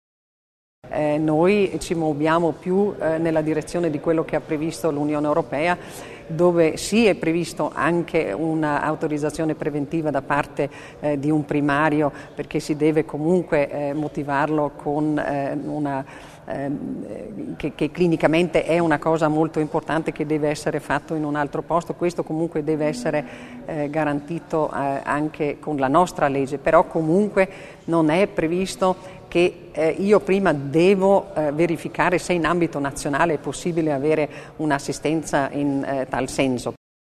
L'Assessore Stocker illustra le novità in tema di mobilità dei pazienti